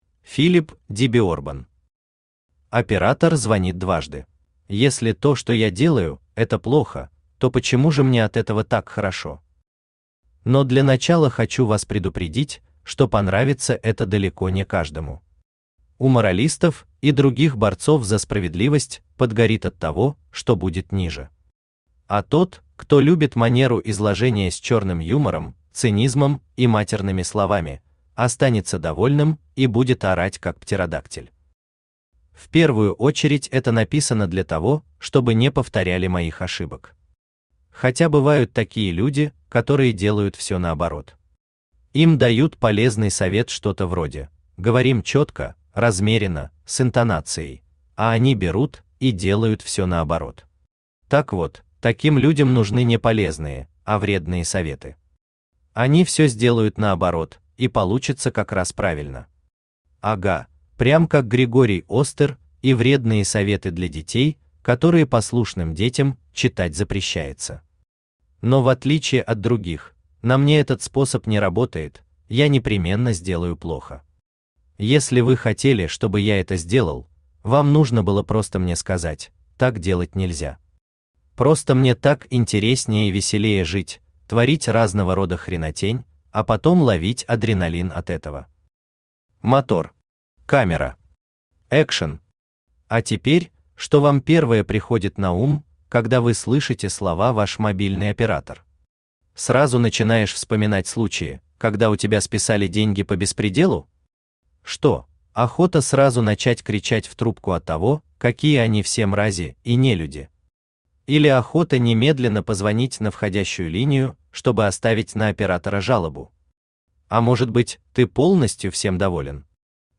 Аудиокнига Оператор звонит дважды
Содержит нецензурную брань.